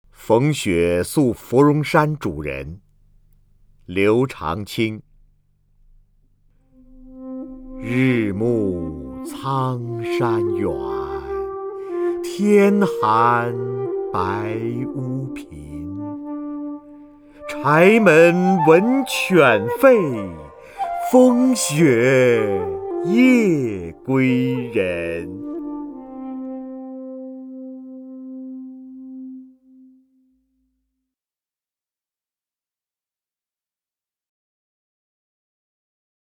瞿弦和朗诵：《逢雪宿芙蓉山主人》(（唐）刘长卿) （唐）刘长卿 名家朗诵欣赏瞿弦和 语文PLUS
（唐）刘长卿 文选 （唐）刘长卿： 瞿弦和朗诵：《逢雪宿芙蓉山主人》(（唐）刘长卿) / 名家朗诵欣赏 瞿弦和